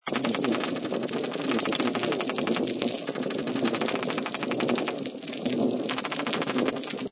Recently, the use of autonomous acoustic recorder data was used to investigate the acoustic soundscape during and after a severe red tide which occurred in 2005.
This study found that while biological sounds from snapping shrimp and fish were abundant in the years without the red tide, biological sounds were largely absent during the red tide, suggesting that the red tide had a considerable effect on fish and snapping shrimp abundance or behavior.